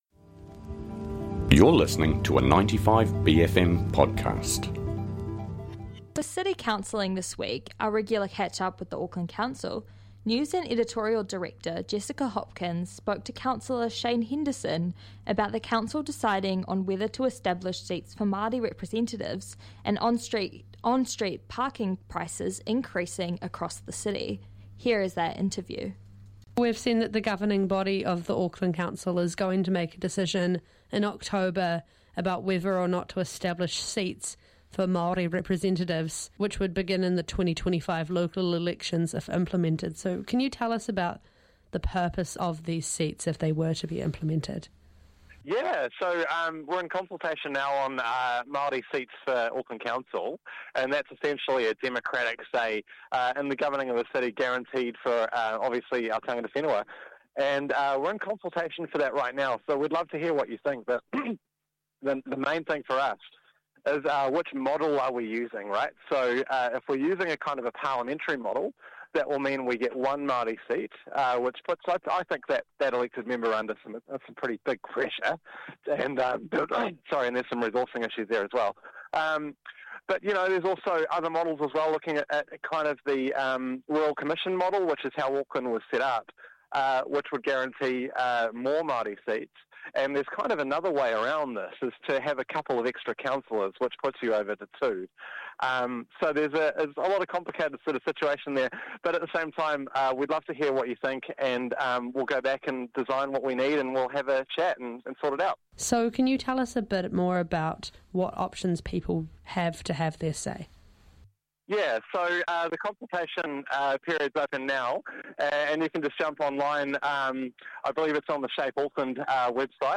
Two alternating Auckland City Councillor's Julie Fairey and Shane Henderson tell us the latest in Council news every Thursday on The Wire.